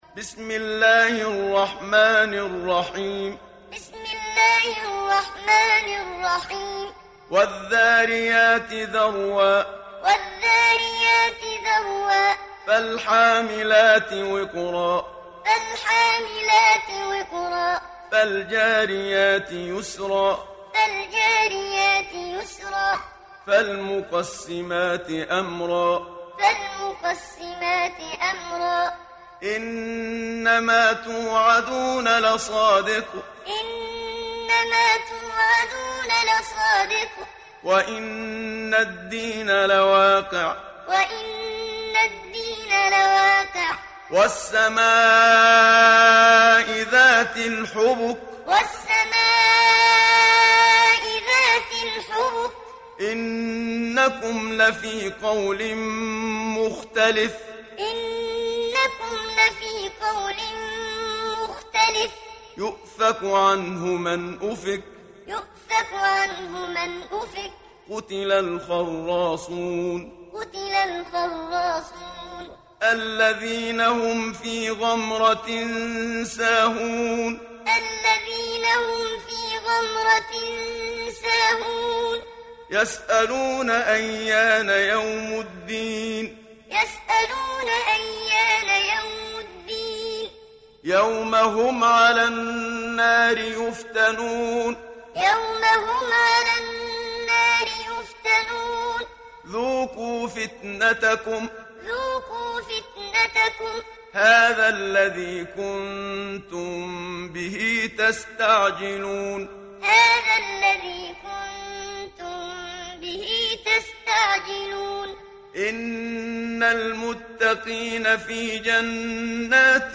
Sourate Ad Dariyat Télécharger mp3 Muhammad Siddiq Minshawi Muallim Riwayat Hafs an Assim, Téléchargez le Coran et écoutez les liens directs complets mp3